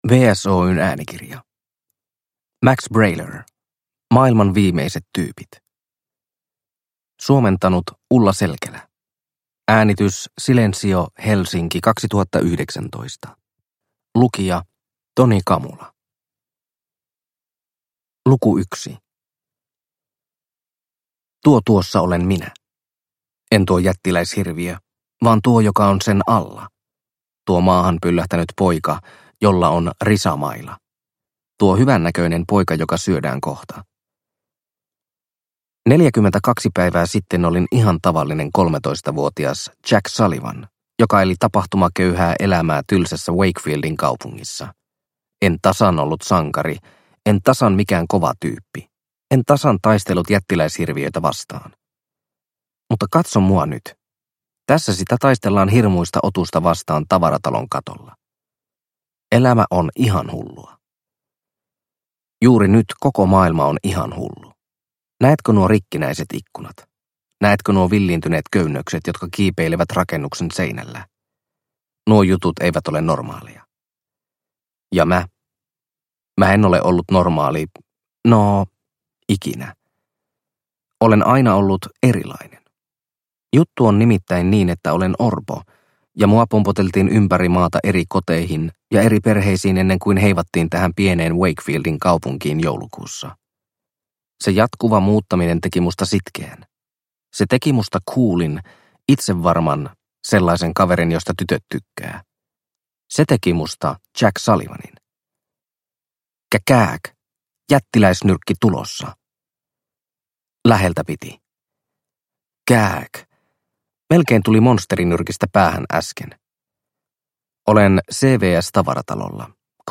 Maailman viimeiset tyypit – Ljudbok – Laddas ner